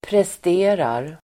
Uttal: [prest'e:rar]